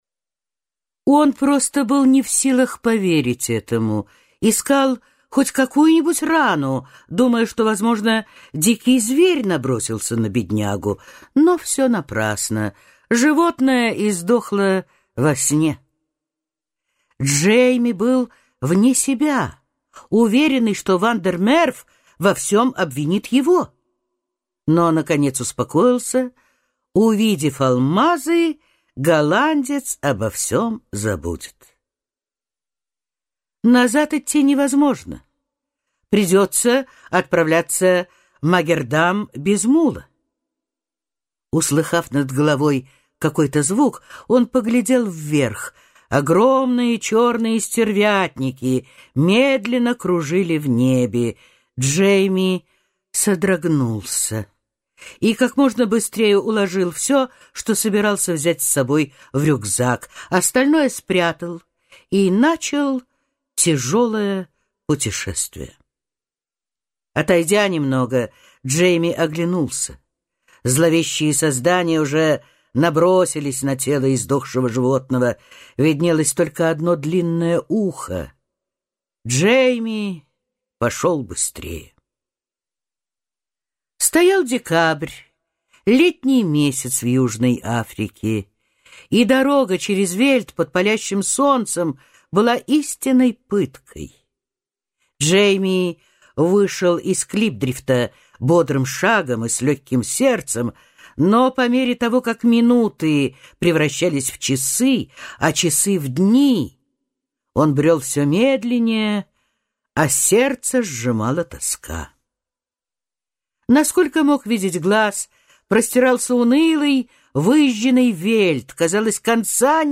Аудиокнига «Интриганка» в интернет-магазине КнигоПоиск ✅ Зарубежная литература в аудиоформате ✅ Скачать Интриганка в mp3 или слушать онлайн